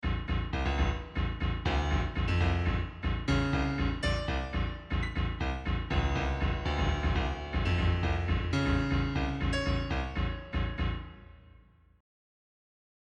громкие
без слов
клавишные
experimental
Тут нет, лишь звук клавиш — в мозг вам бьёт.